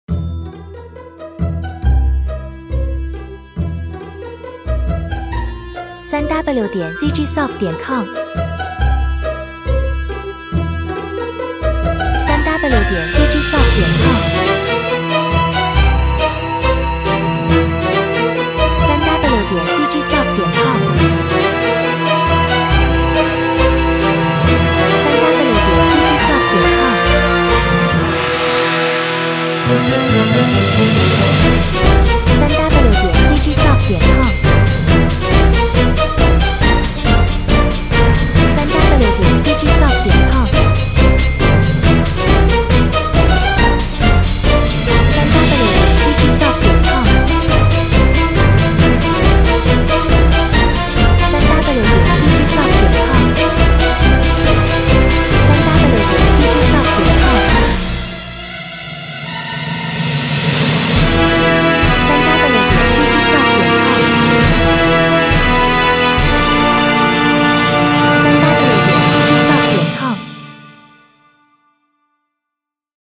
16-Bit Stereo，44.1 kHz
138 BPM
人声:没有人声：